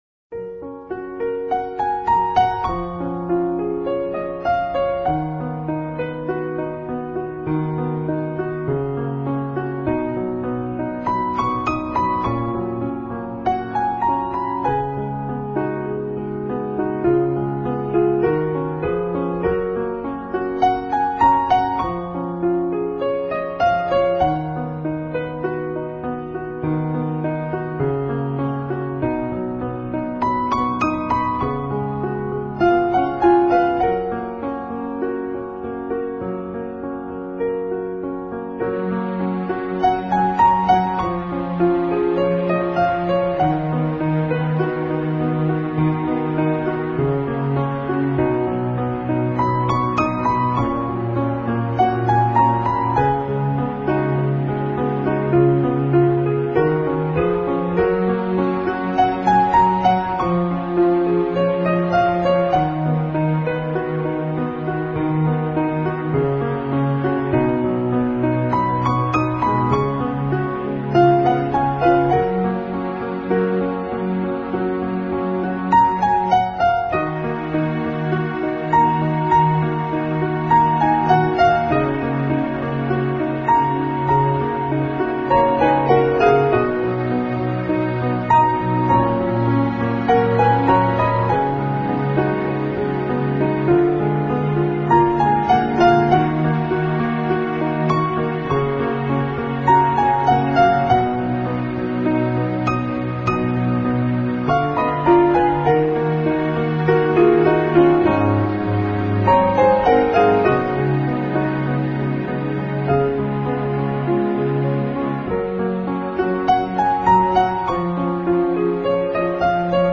专辑语言：纯音乐